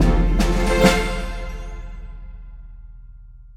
Category 🎮 Gaming
bounce game jump jumping trampoline sound effect free sound royalty free Gaming